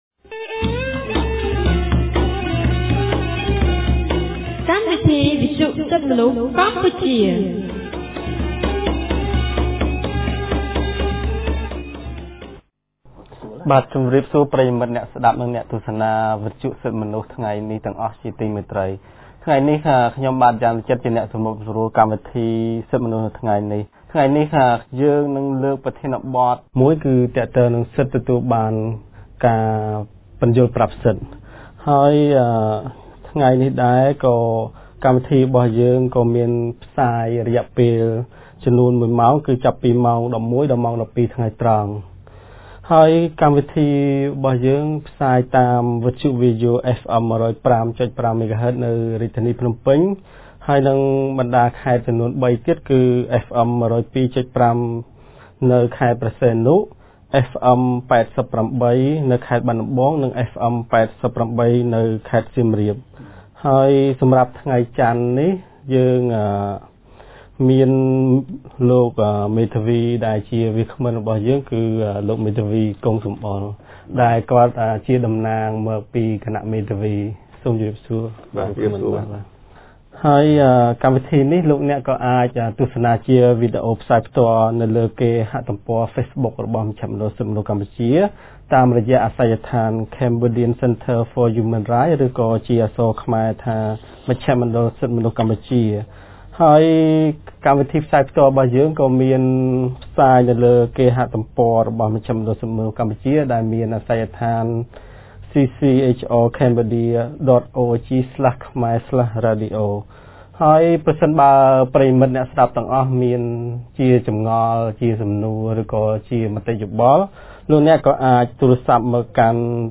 ថ្ងៃចន្ទ ទី១៩ ខែសីហា ឆ្នាំ២០១៩ គម្រាងសិទ្ធិទទួលបានការជំនុំជម្រះដោយយុត្តិធម៌នៃមជ្ឈមណ្ឌលសិទ្ធិមនុស្សកម្ពុជា បានរៀបចំកម្មវិធីវិទ្យុក្រោមប្រធានបទស្តីពី សិទ្ធិទទួលបានការពន្យល់ប្រាប់សិទ្ធិ។